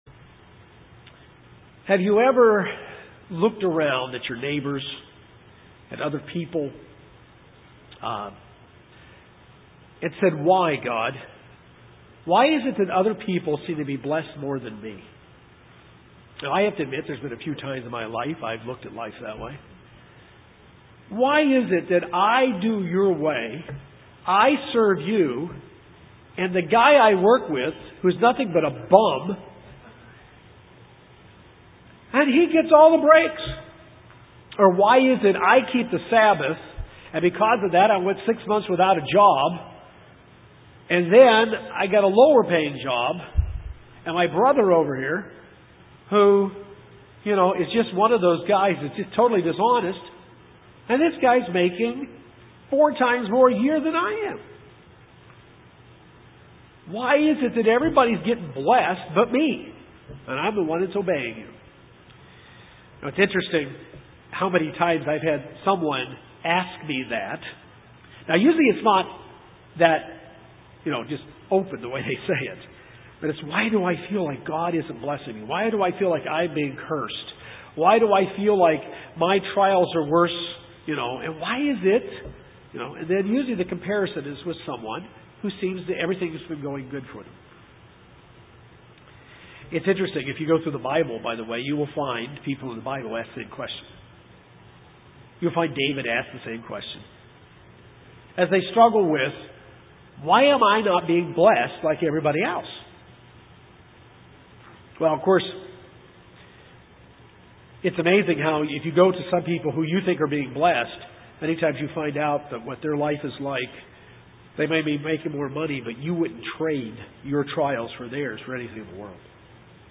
This sermon will look at the simplicity of why we think this way some times.